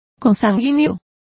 Also find out how consanguinea is pronounced correctly.